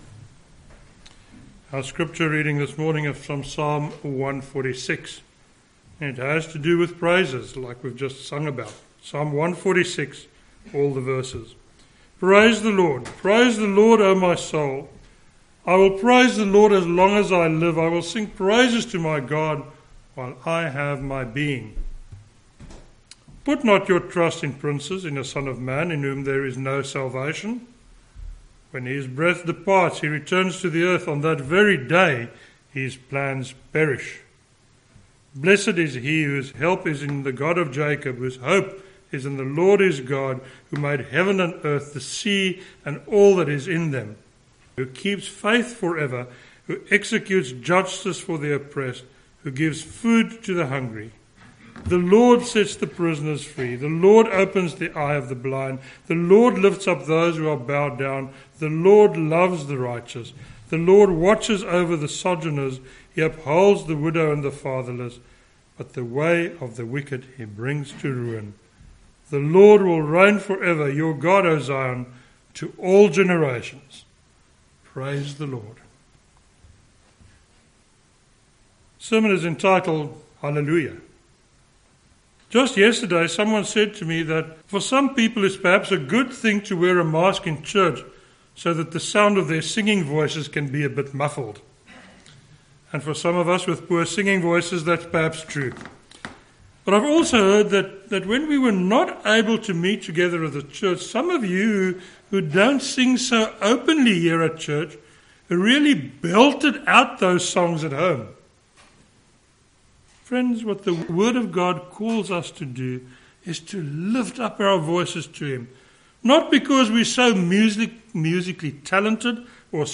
a sermon on Psalm 146